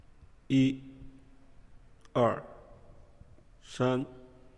描述：用中文慢慢数1、2、3